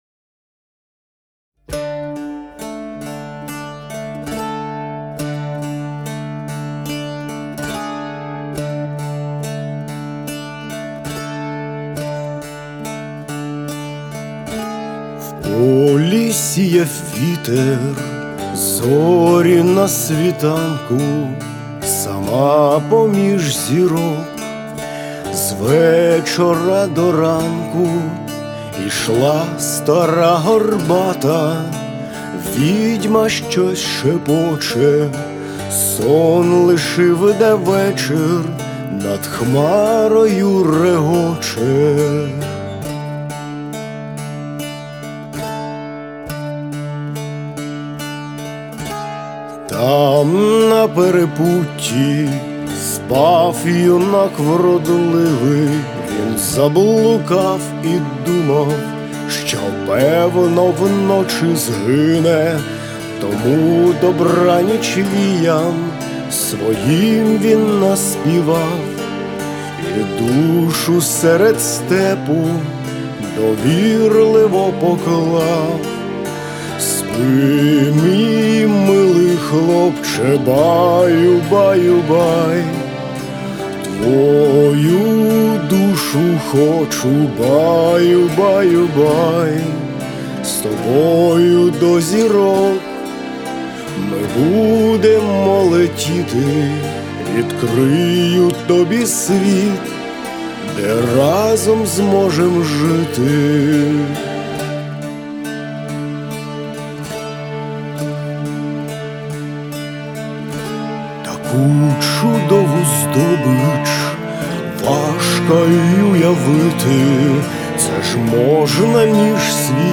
• Жанр: Rock, Folk